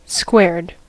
squared: Wikimedia Commons US English Pronunciations
En-us-squared.WAV